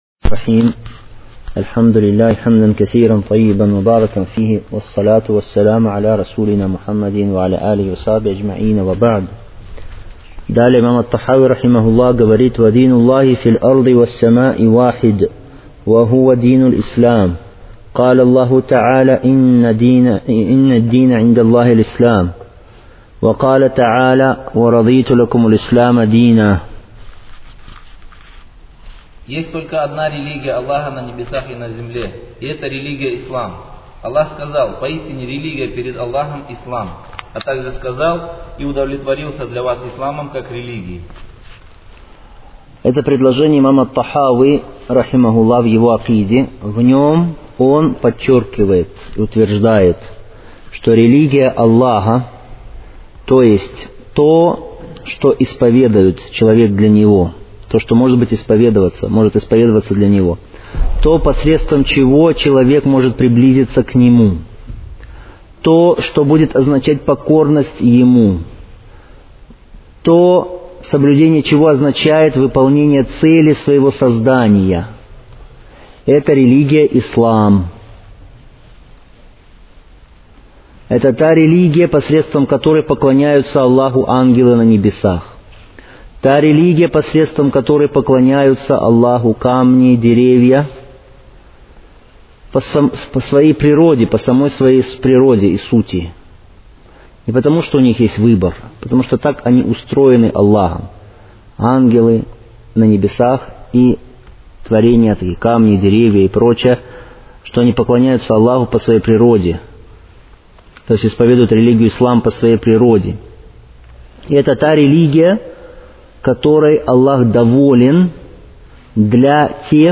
Серия уроков в пояснении книги «Акида Тахавия».